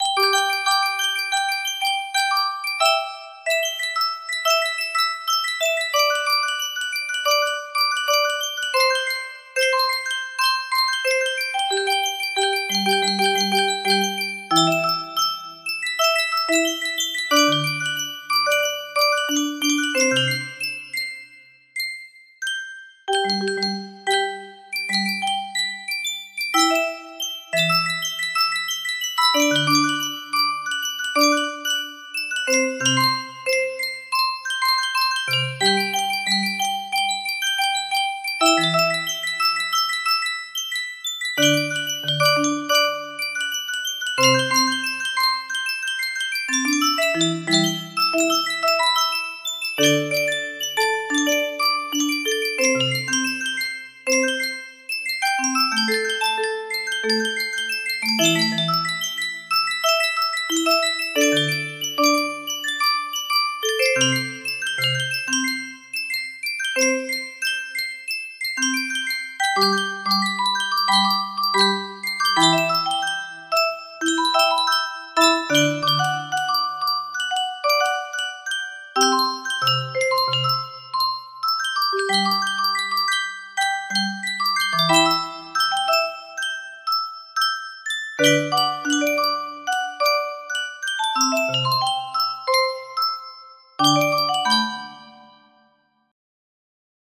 Silhouette of Sorrow music box melody